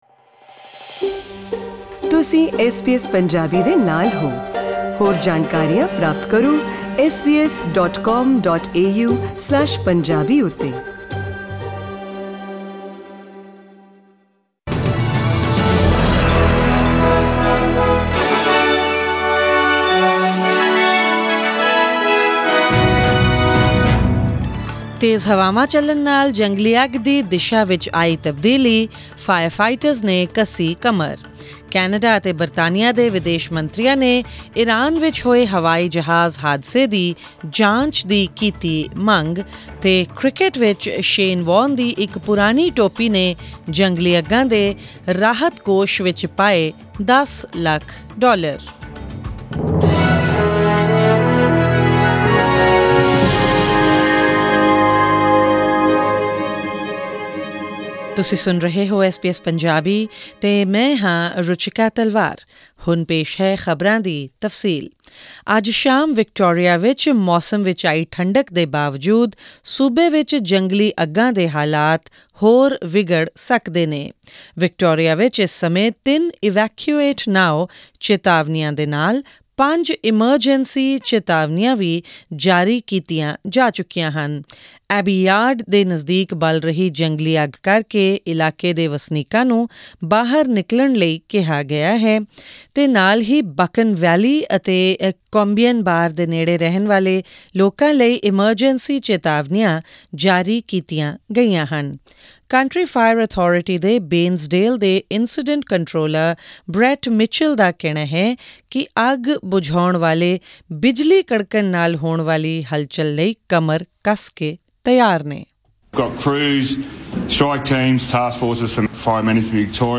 Presenting the major national and international news stories of today with updates on sports, currency exchange rates and the weather forecast for tomorrow.